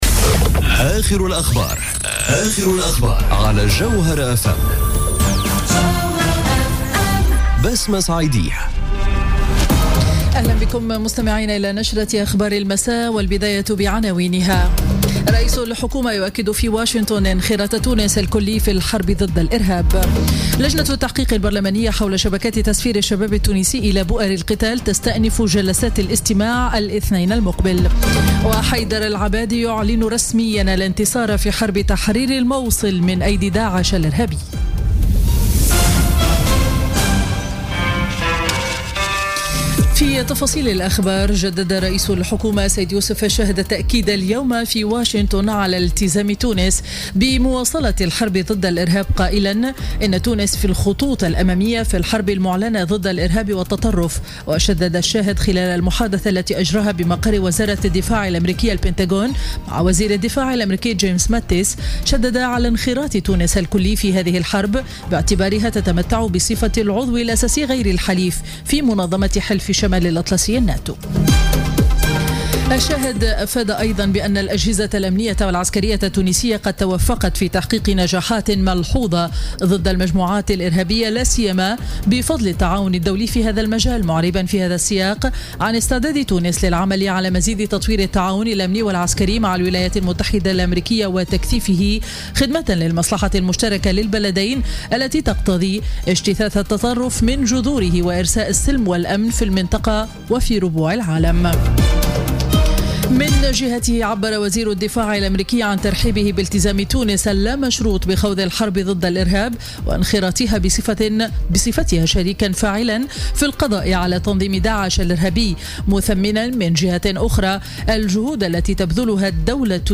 نشرة أخبار السابعة مساء ليوم الاثنين 10 جويلية 2017